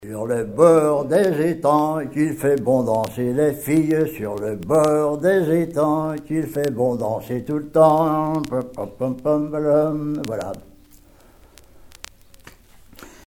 Divertissements d'adultes - Couplets à danser
Pièce musicale éditée